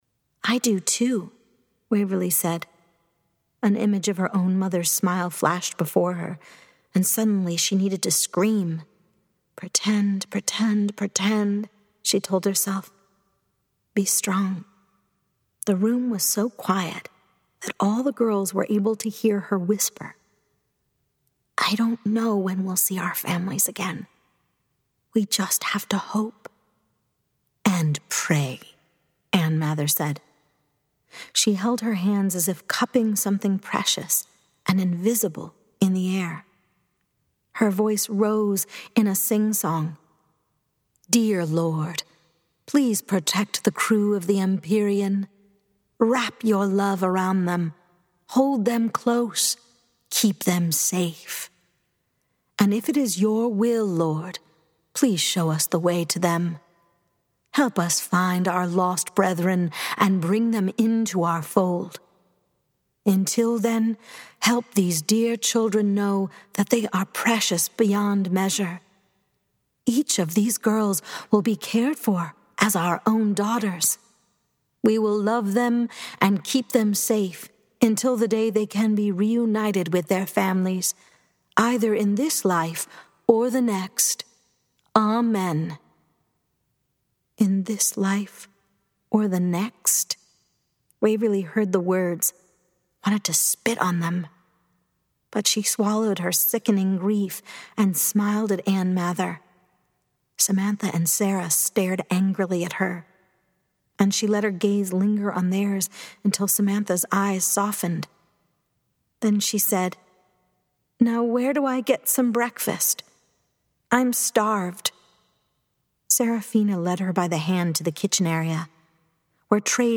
Audiobook - Voice-Over Performer